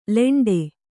♪ leṇḍe